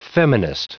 Prononciation du mot feminist en anglais (fichier audio)
Prononciation du mot : feminist